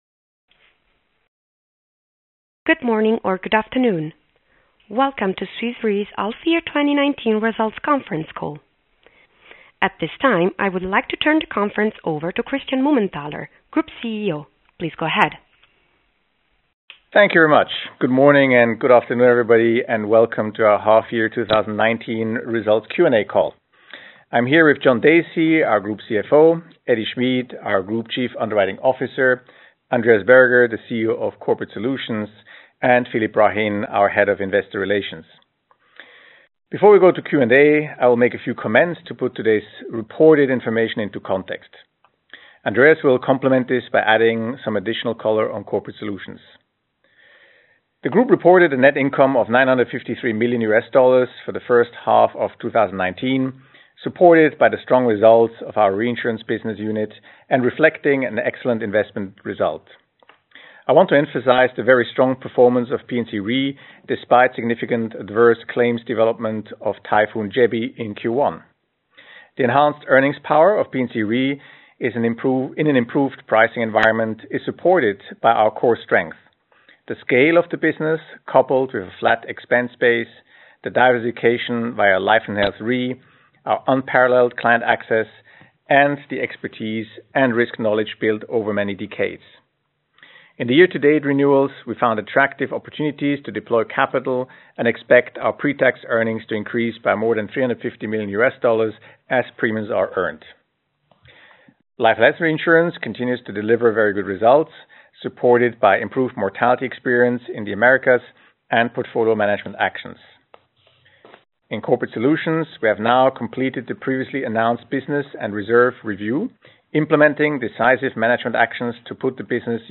hy-2019-call-recording.mp3